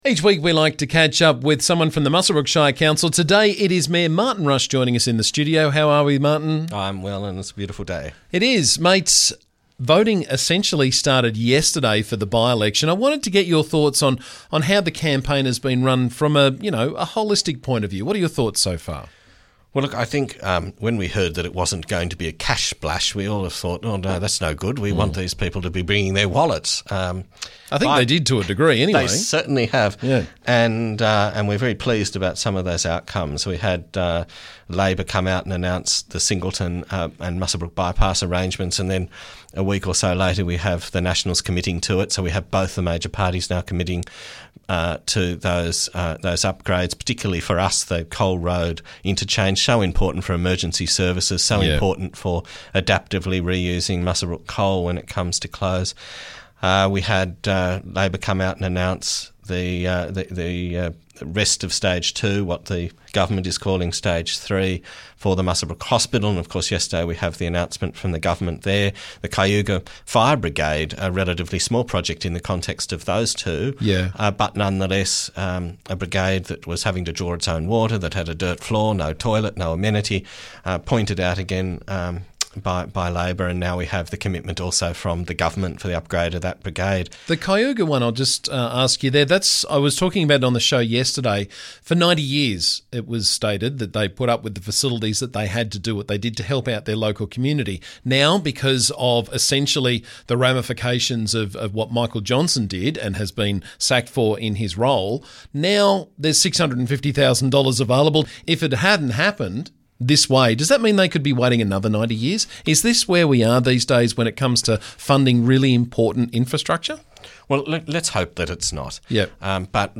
Muswellbrook Shire Council Mayor Martin Rush joined me to talk about the By-Election this morning.